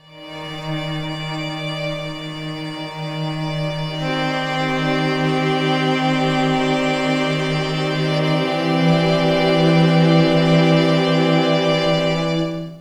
Index of /90_sSampleCDs/Zero-G - Total Drum Bass/Instruments - 2/track57 (Strings)